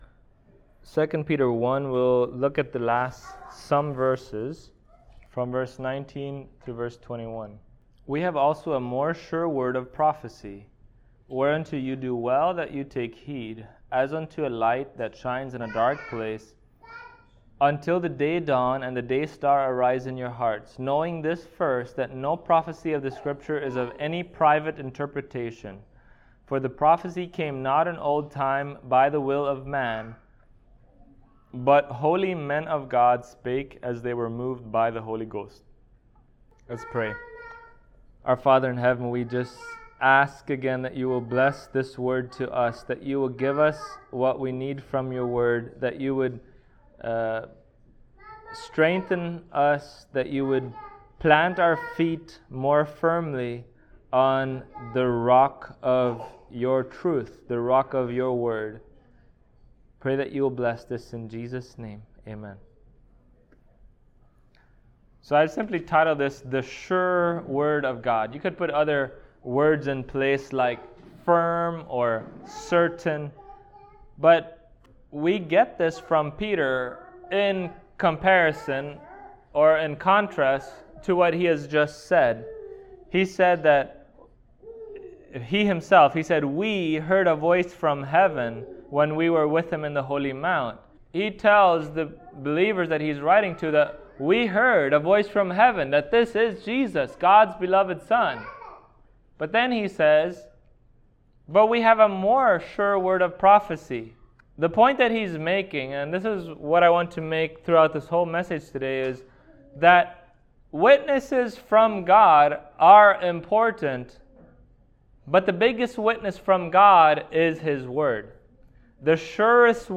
2 Peter Passage: 2 Peter 1:19-21 Service Type: Sunday Morning Topics